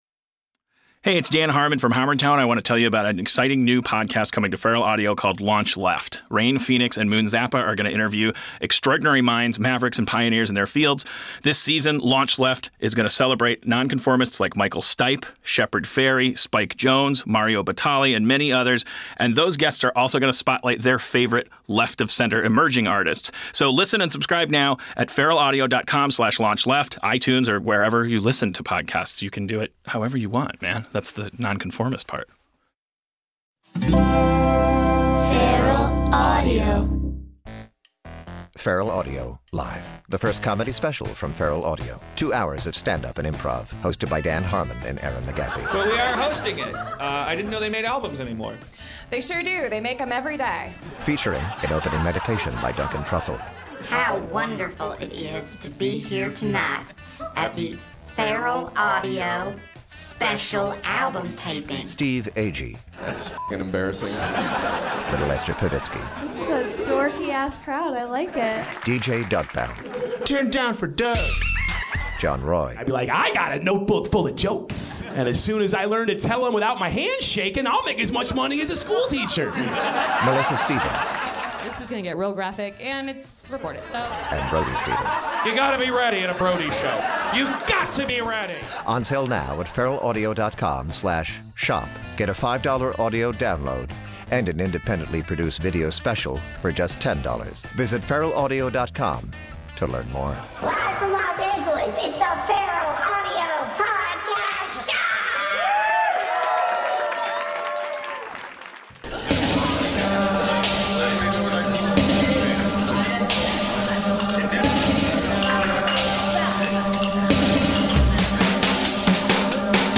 The magnanimous family hour prince, Duncan Trussell, returns for a third illustrious round of talks, but this time its pure haywire. Intergalactic broad-spectrum magnetic field interruptions have wreaked havoc with the normal way of talking. its pure bent genius!